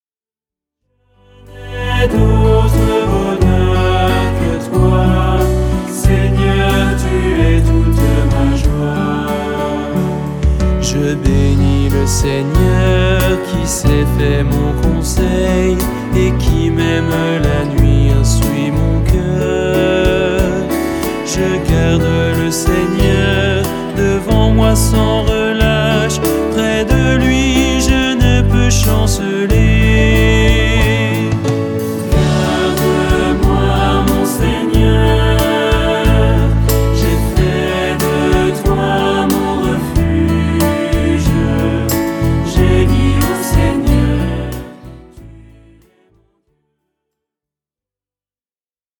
Célèbres chants de Louange